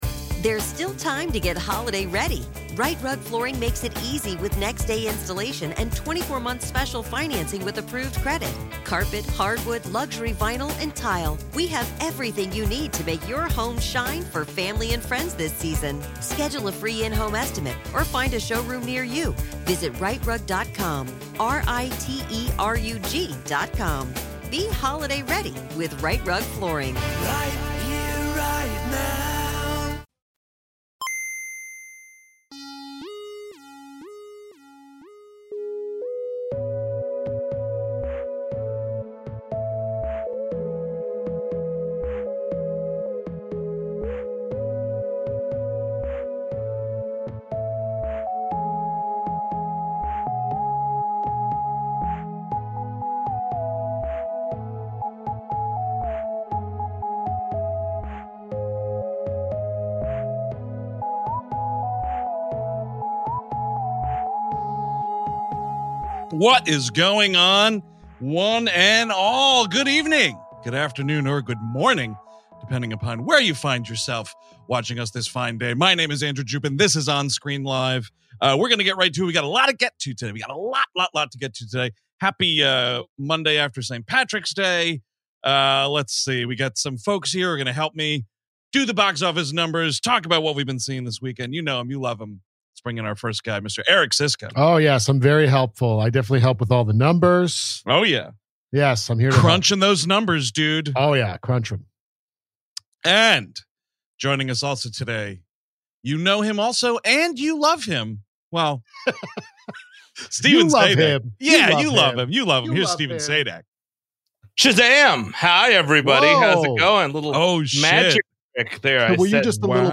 On this week's On-Screen Live!, the guys are reporting from the theater as they share their thoughts on Shazam! Fury of the Gods and more!
This is of course the audio-only edition of On-Screen Live! , if you want the full experience, check out the show on our YouTube channel.